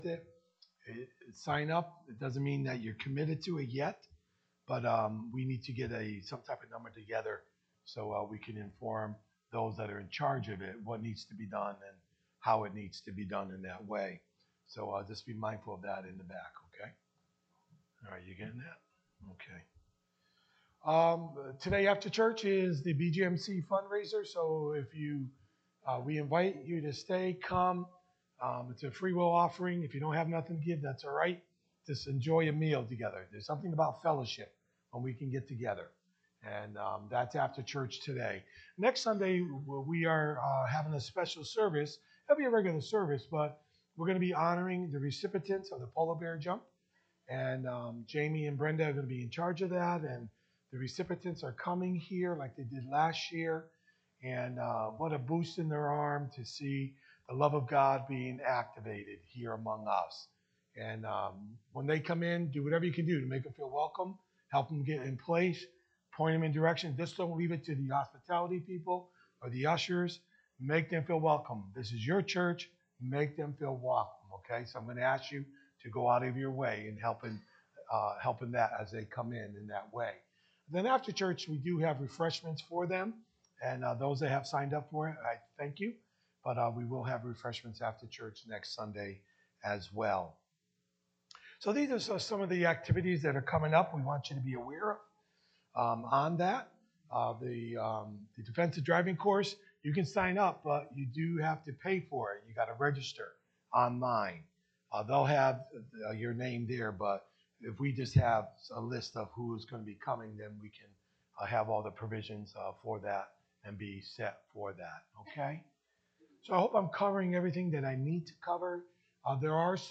Sermons | Oneonta Assembly of God